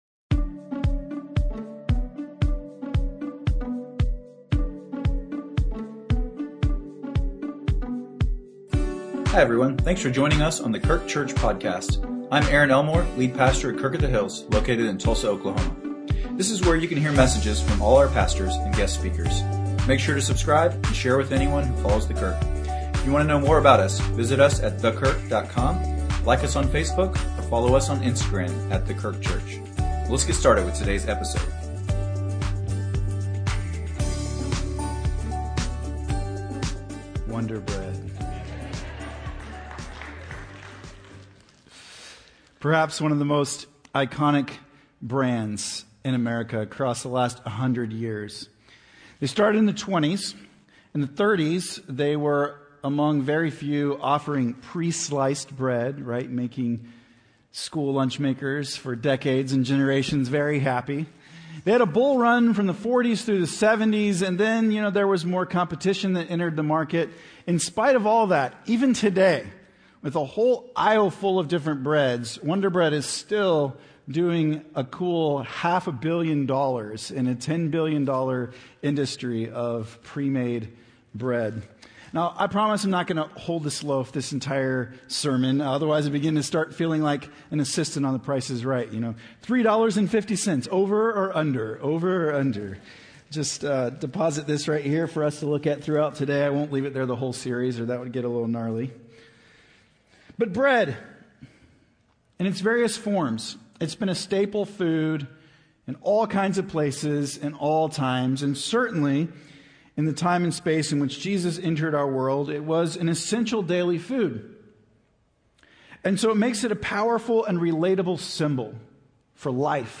Sermons | The Kirk - Tulsa, OK